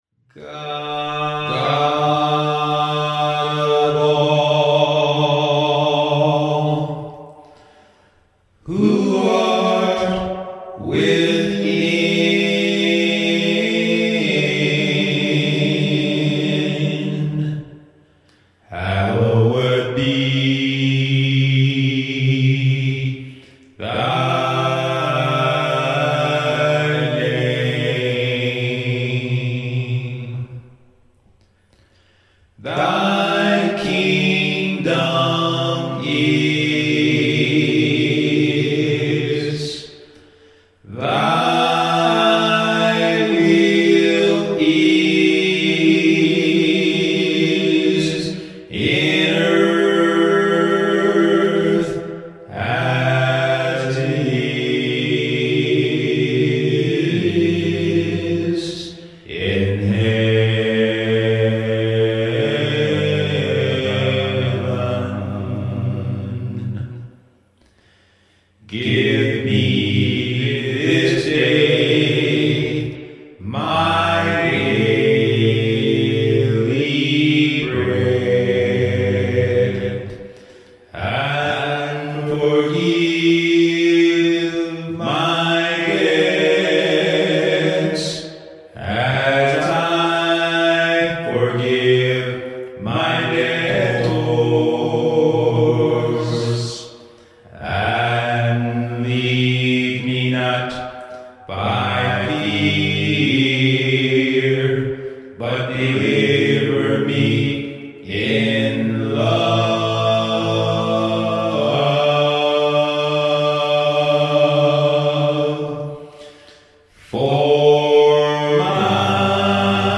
I was moved to sing it.